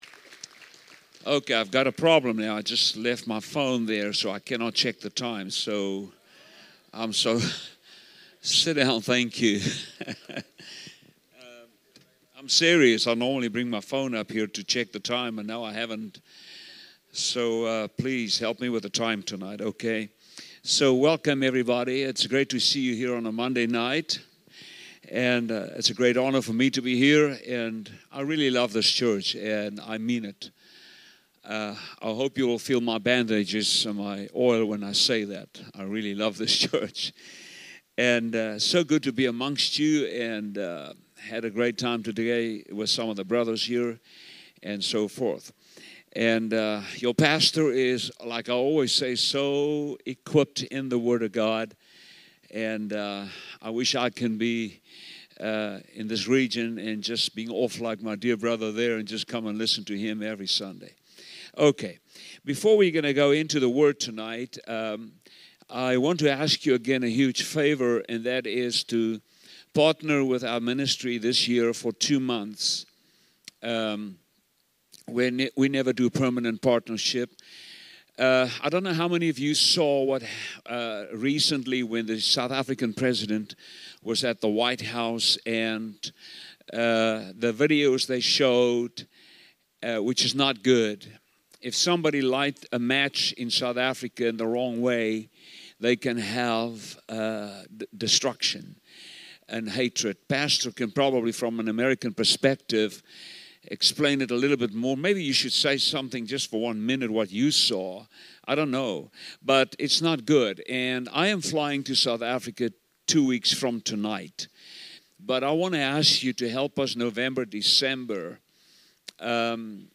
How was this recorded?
2025 Evening Service